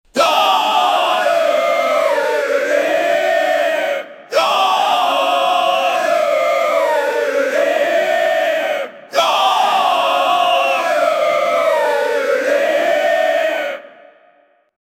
Goblins Sound Effects - Free AI Generator & Downloads